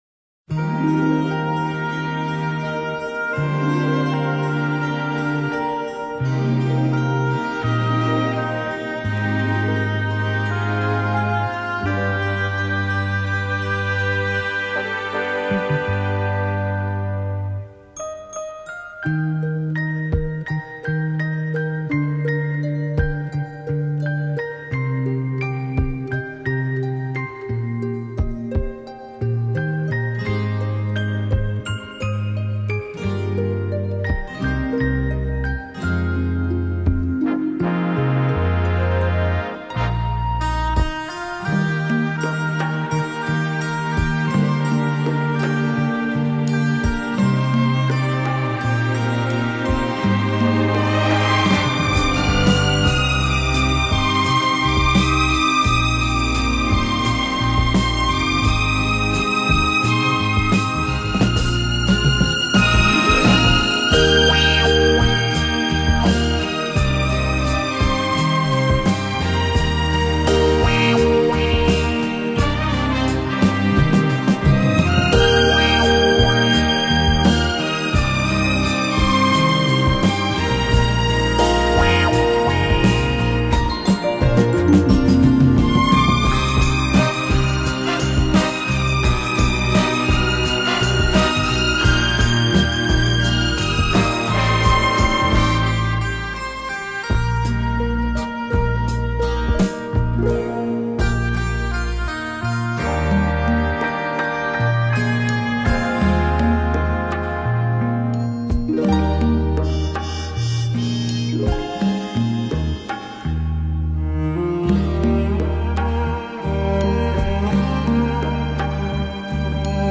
精品轻音乐